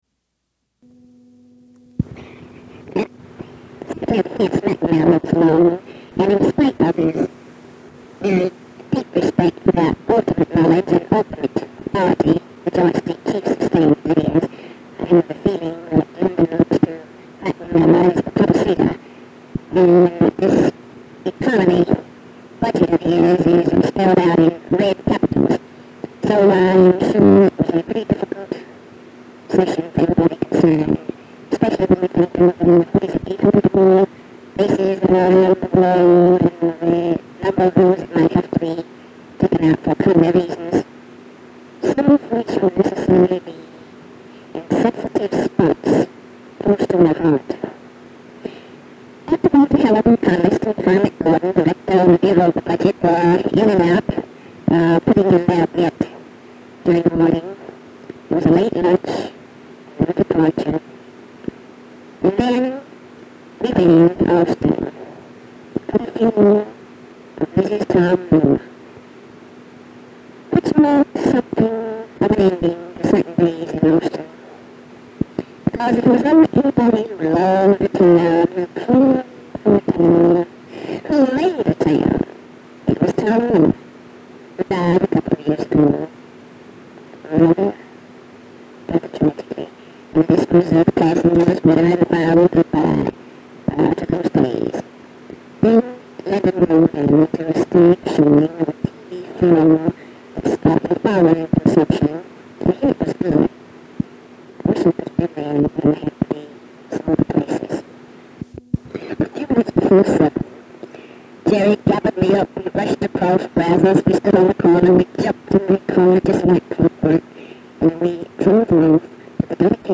Audio diary and annotated transcript, Lady Bird Johnson, 12/30/1963 (Monday) | Discover LBJ